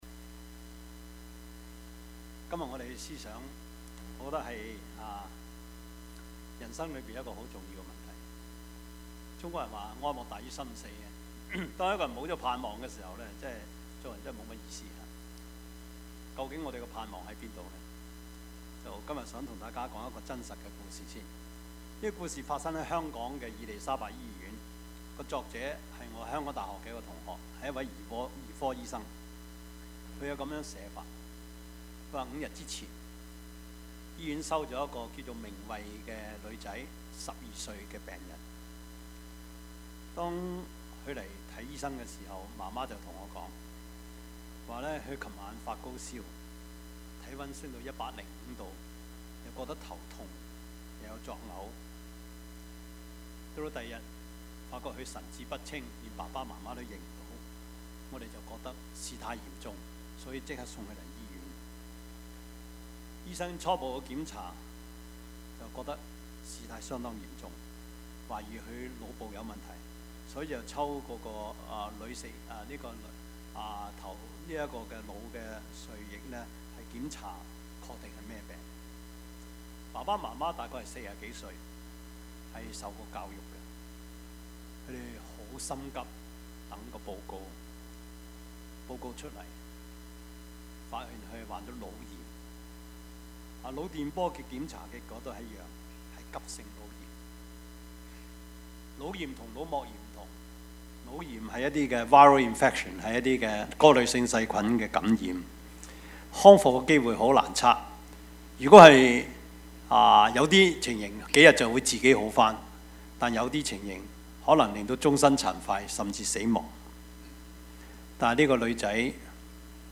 Passage: 傳道書 9:1-6 Service Type: 主日崇拜
Topics: 主日證道 « 一個良好的政府 空的墳墓 »